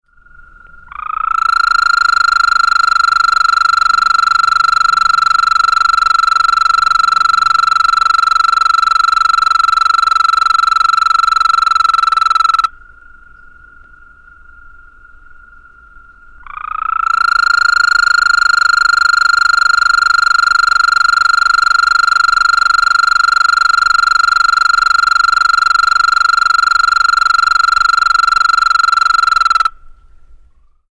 Advertisement Calls
The advertisement call of the Eastern American Toad is a long musical trill lasting from 6 to 30 seconds.
sound  This is a 30 second recording of two advertisement calls of a male Eastern American Toad recorded at night at a small slow creek in Monroe County, Tennessee. (shown on the right.)
The space between the calls has been shortened considerably (from about 25 seconds.)